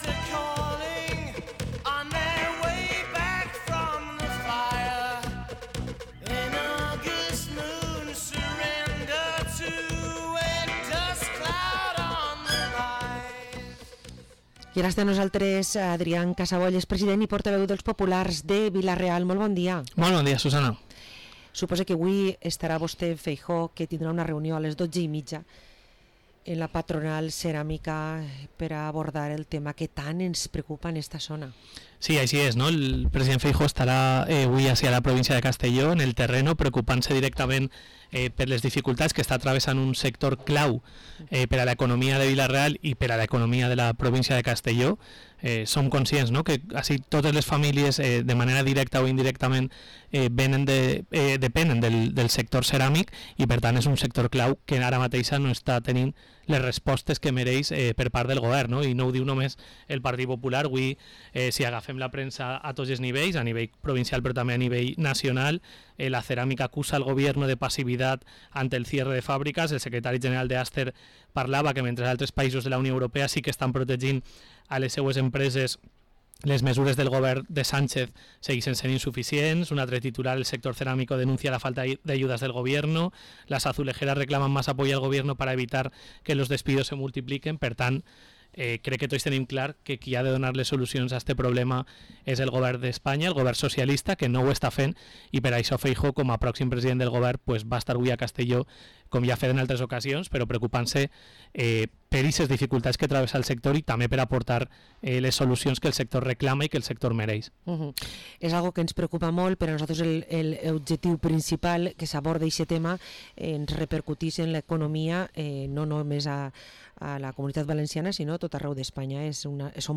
Entrevistem al portaveu i president del PP de Vila-real, Adrián Casabó, que ens parla de ceràmica, de la seua candidatura a l’alcaldia que s’ha fet oficial en els últims dies i del pl perquè l’educació gratuïta de 0 a 3 anys siga gratuïta.